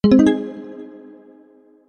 AddScore.mp3